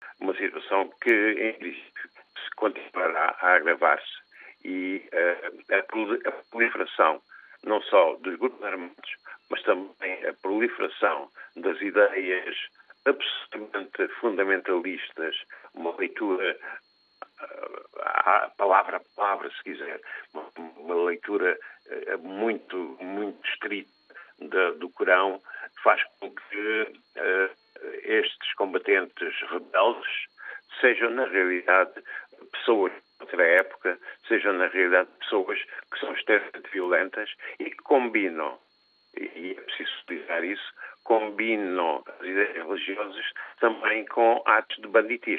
Agravou-se a situação militar no Mali, depois dos ataques deste fim de semana, em vários pontos do país. Ataques coordenados por grupos ligados à Al-Qaida, e às forças Tuaregue do Norte do país. A rádio RTP/África falou esta manhã com o antigo enviado do Secretário-Geral da ONU para o Sahel.